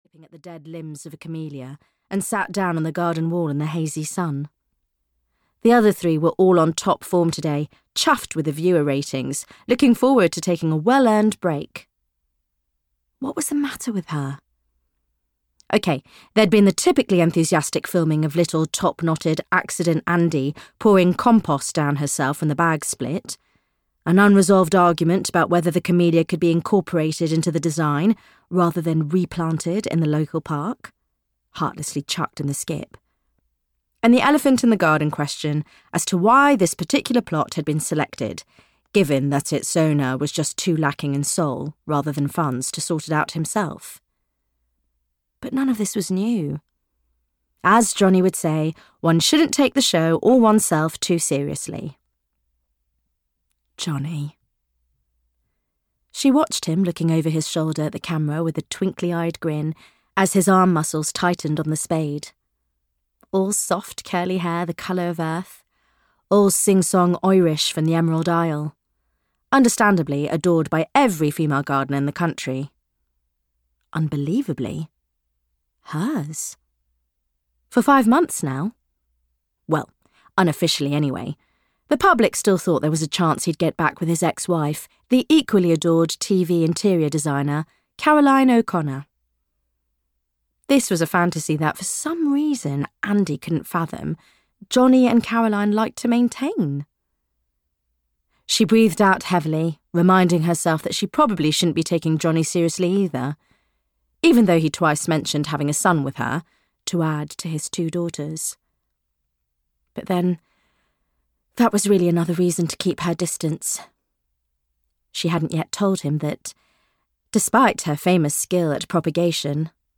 The Spanish Garden (EN) audiokniha
Ukázka z knihy